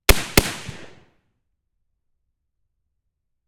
Pole Position Production - FN FAL 7.62x51mm